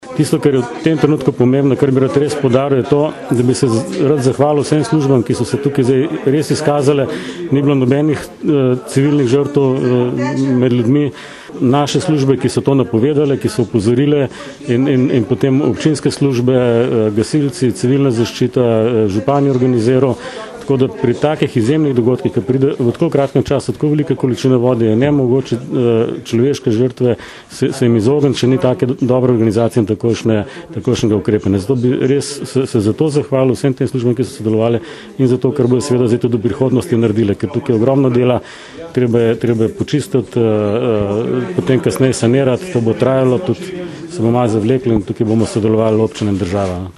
izjava_mag.borutsajoviczupanobcinetrzic_poplave.mp3 (3,3MB)